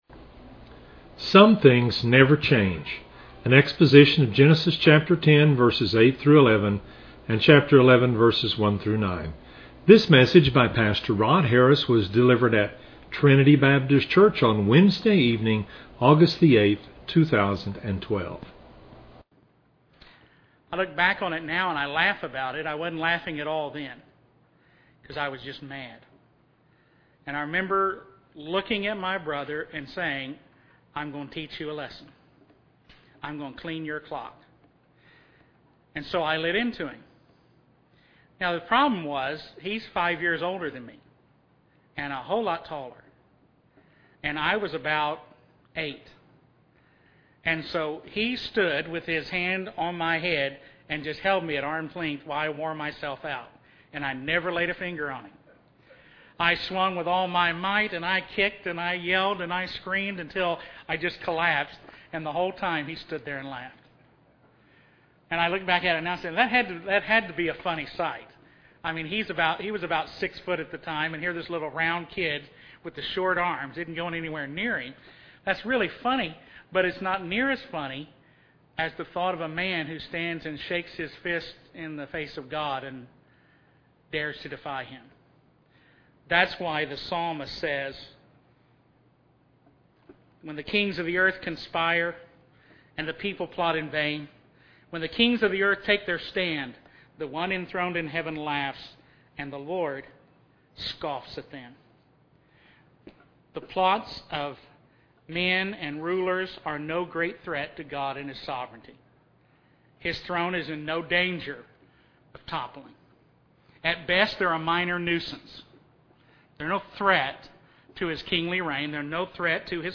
This is an exposition of Genesis 10:8-11; 11:1-9.